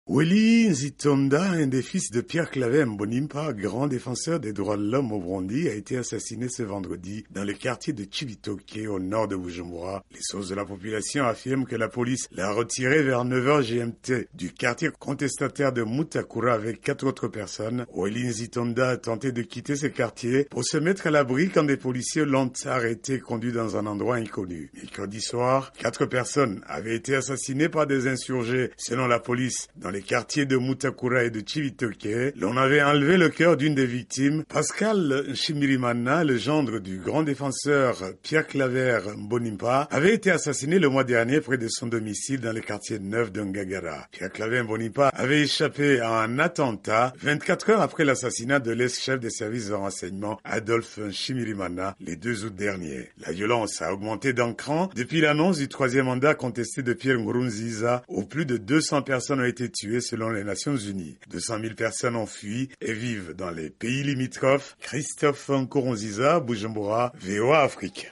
Reportage à Bujumbura